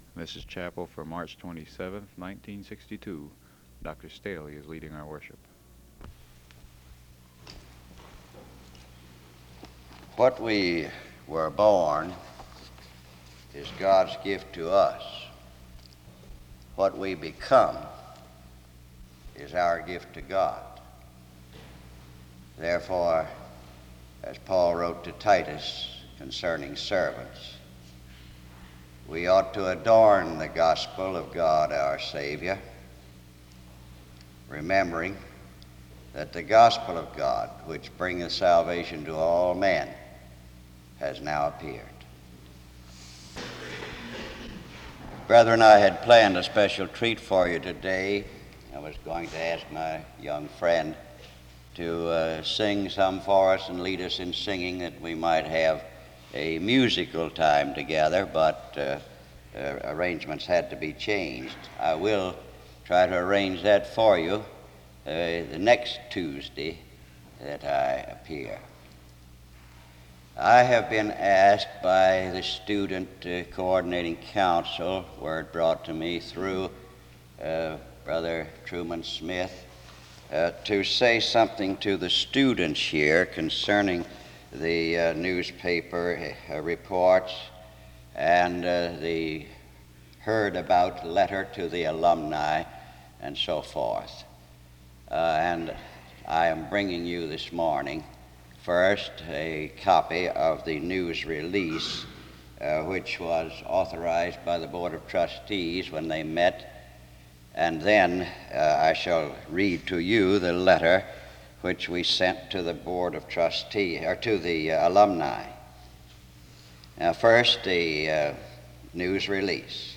A closing hymn is played from 21:45-22:51.